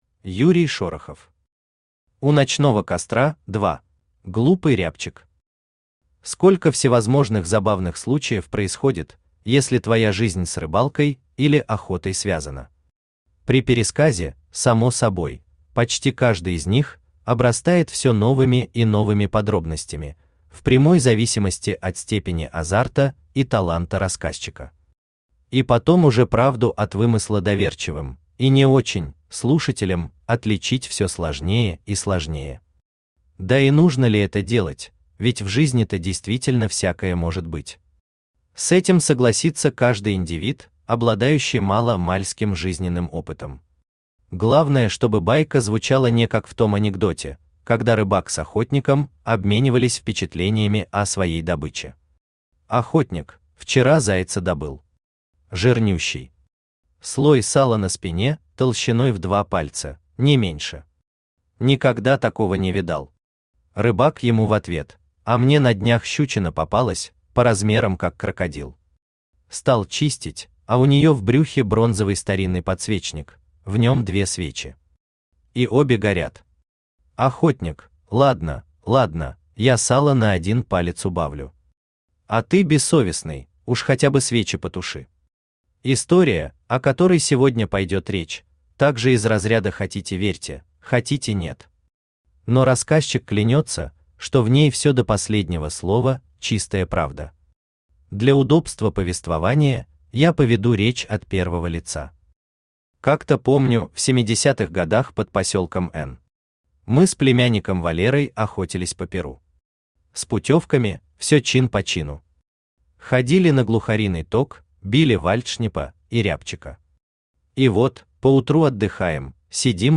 Аудиокнига У ночного костра – 2 | Библиотека аудиокниг
Aудиокнига У ночного костра – 2 Автор Юрий Шорохов Читает аудиокнигу Авточтец ЛитРес.